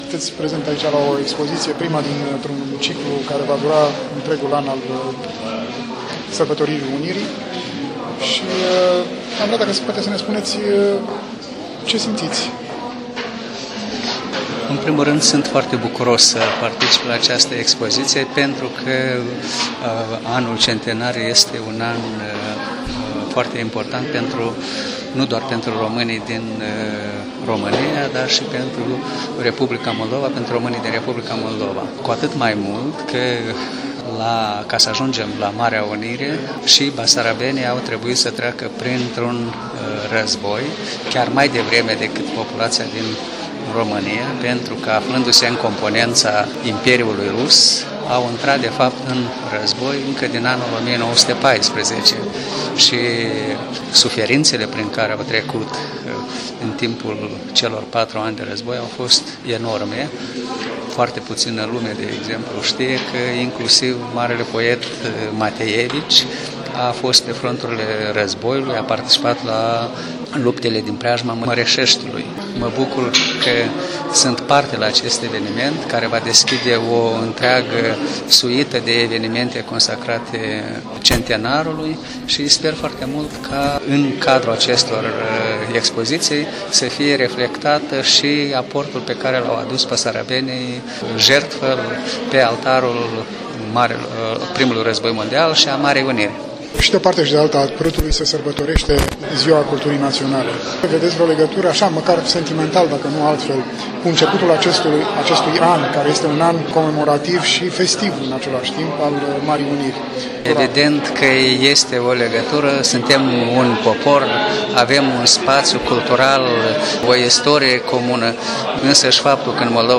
Interviu cu ambasadorul Republicii Moldova la București.
Printre participanții la deschiderea expoziției s-a aflat și amabasadorul Republicii Moldova la București Mihai Gribincea. L-am rugat să ne spună care este semnificația prezenței sale la această expoziție.